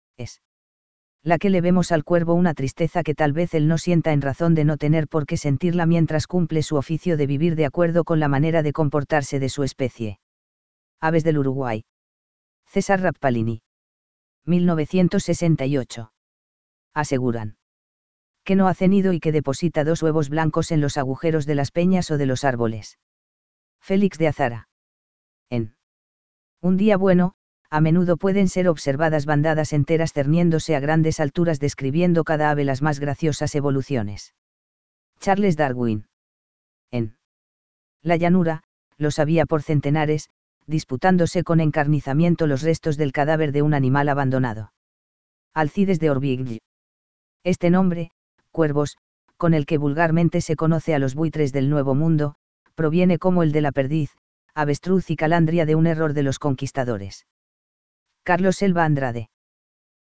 Buitrecabezanegra2.mp3